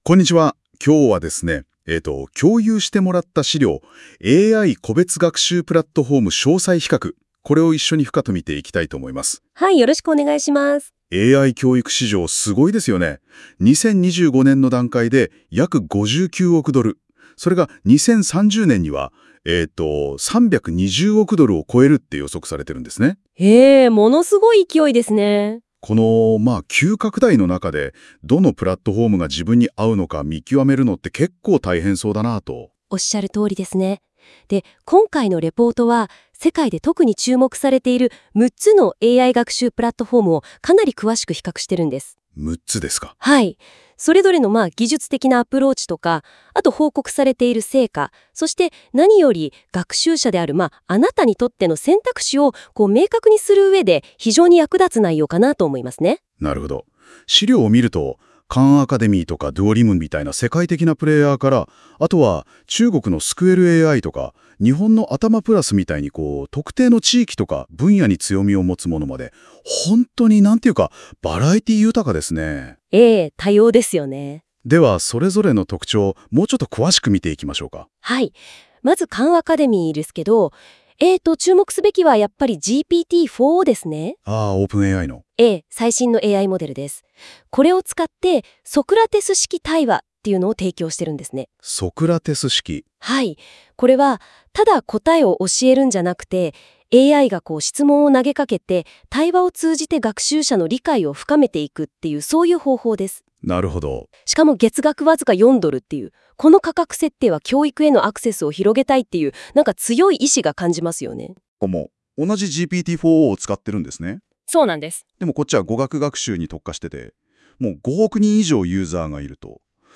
🎧 この記事の解説音声
忙しい方のために、この記事をポッドキャスト形式で解説しています。
※ この音声は Google NotebookLM を使用して記事内容から生成されています。